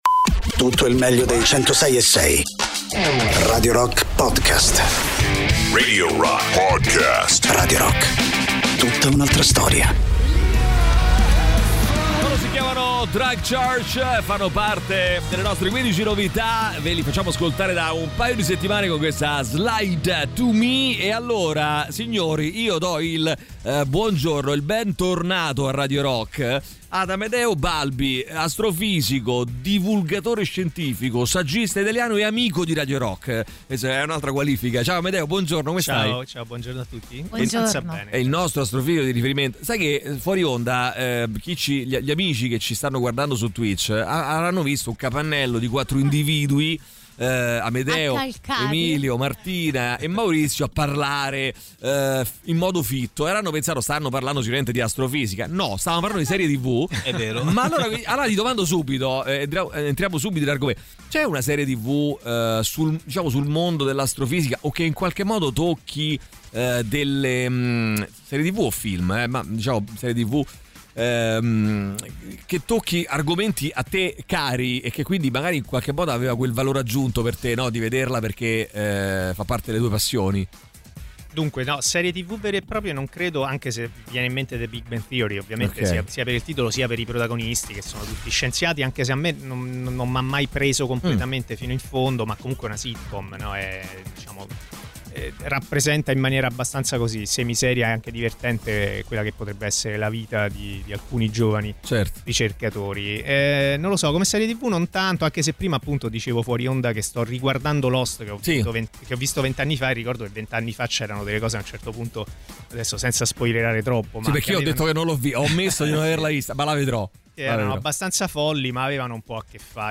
Interviste: Amedeo Balbi (08-10-24)